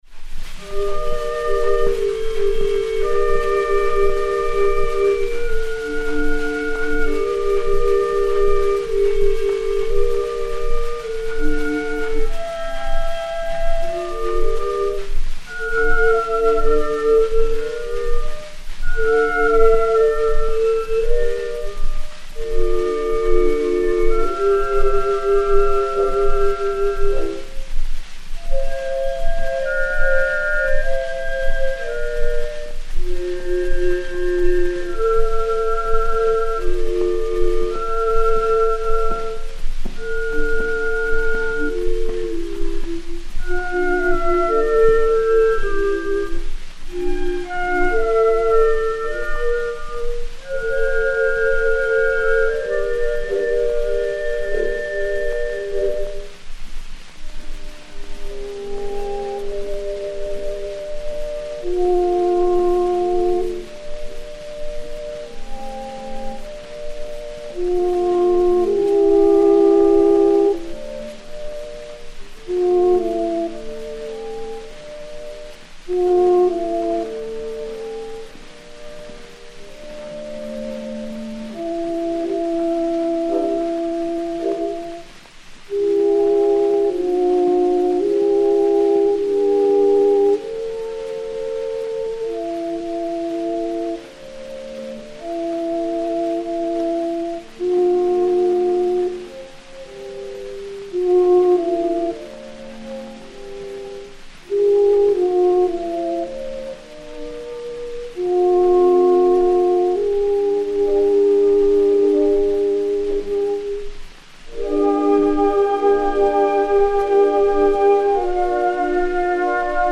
Musique de la Garde Républicaine
Pathé 80 tours n° 6346, mat. 5994, enr. le 14 novembre 1919